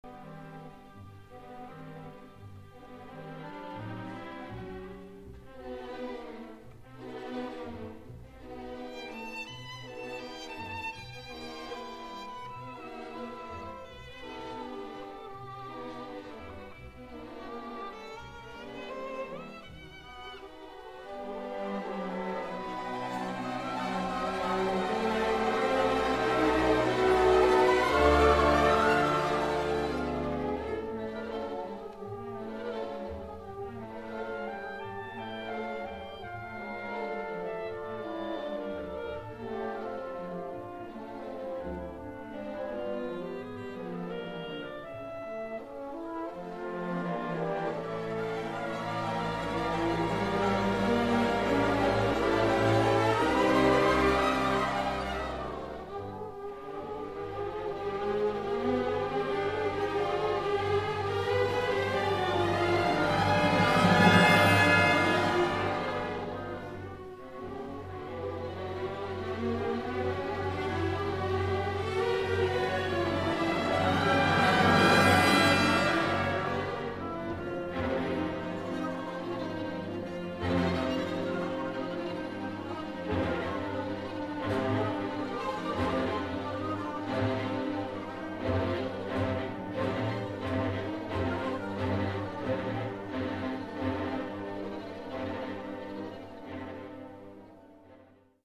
名手達その２：ヴァイオリン、クラリネット　１９９４年
そして、それに引き継いでクラリネットのソロも立派である。
惜しむらくは、もう少し音量があれば・・・。弦楽器の細かい音符も克明に弾かれていて、油断すると？金大フィルだということを忘れる。
特に弦楽器の好調ぶりが目立つ。
１９９４年　第５４回定期演奏会より　　指揮：小松一彦